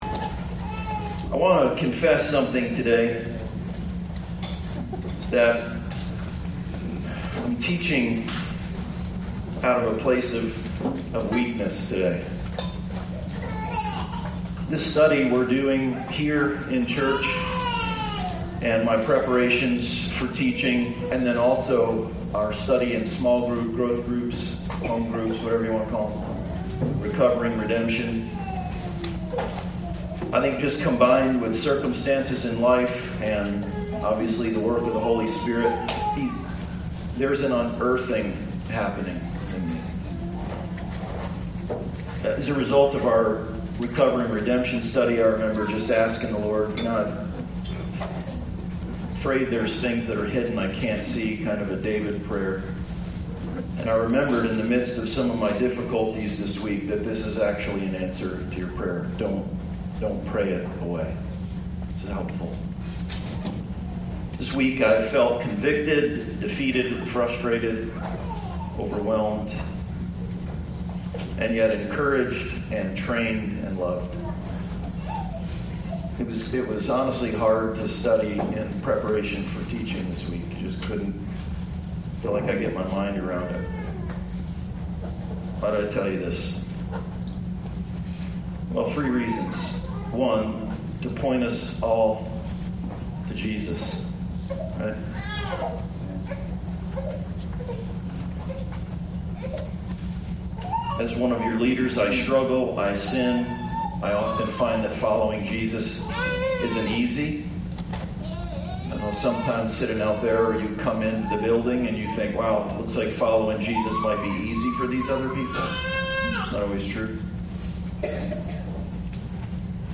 Identity Formation Passage: Romans 4:16-25 Service Type: Sunday Service « God is…
sermon-18.mp3